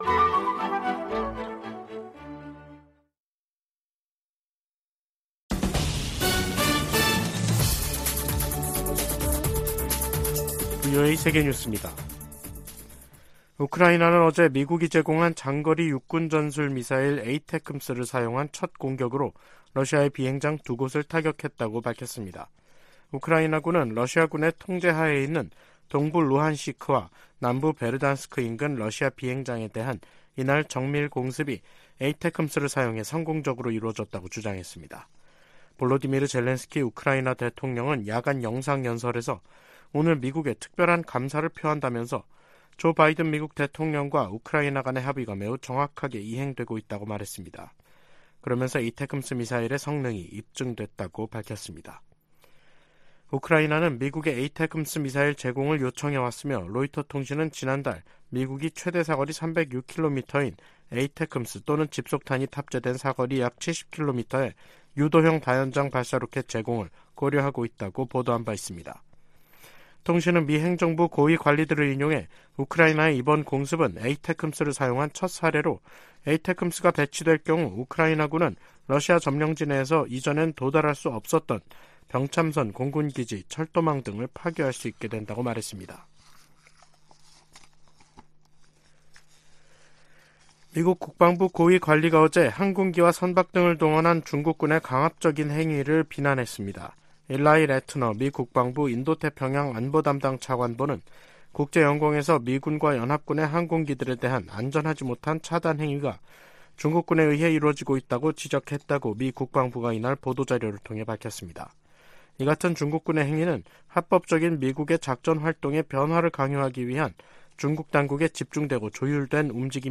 VOA 한국어 간판 뉴스 프로그램 '뉴스 투데이', 2023년 10월 18일 2부 방송입니다. 미 국무부가 러시아 외무장관 방북과 관련해 러시아가 첨단 군사기술을 북한에 넘길 것을 우려하고 있다고 밝혔습니다. 미 인도태평양사령관은 북한과 러시아 간 무기 거래 등 최근 움직임으로 역내 위험성이 커졌다고 지적했습니다. 팔레스타인 무장 정파 하마스가 가자지구에서 북한제 무기를 사용한다고 주한 이스라엘 대사가 VOA 인터뷰에서 말했습니다.